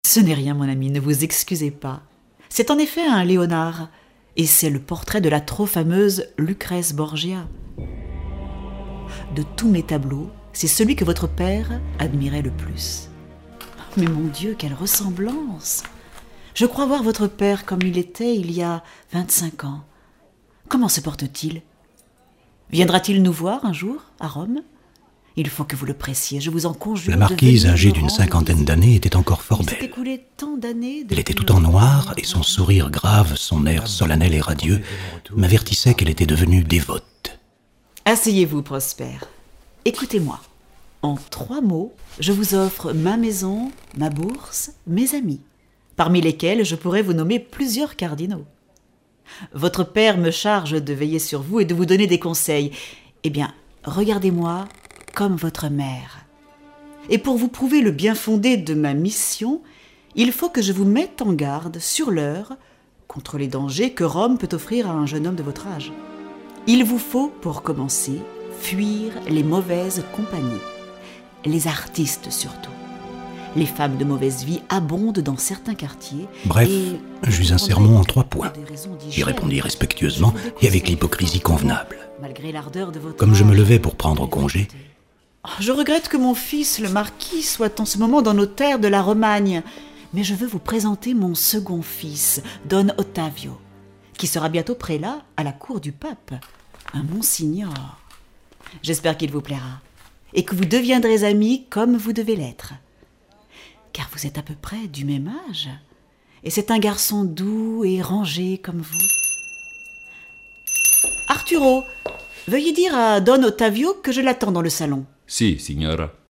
Diffusion distribution ebook et livre audio - Catalogue livres numériques
L’adaptation, entièrement mise en espace comme une bande-son cinématographique, est servie par cinq comédiens et une partition originale.